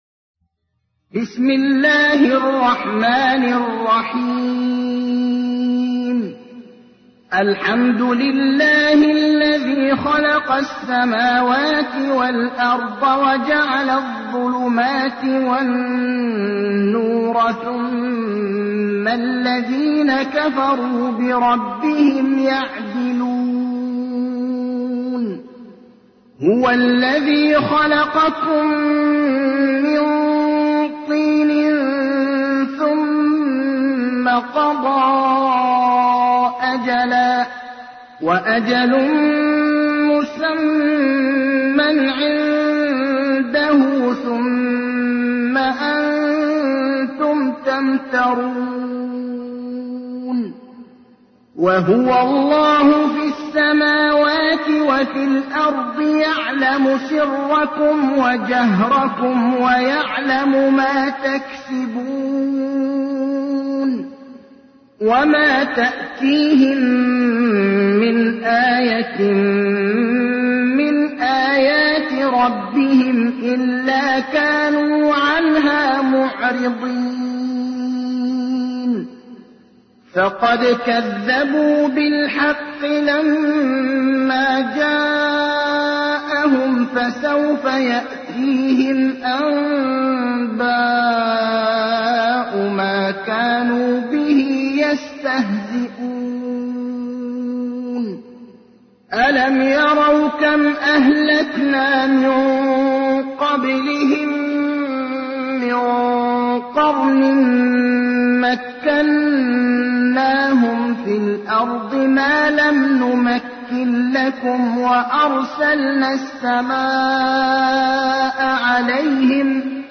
تحميل : 6. سورة الأنعام / القارئ ابراهيم الأخضر / القرآن الكريم / موقع يا حسين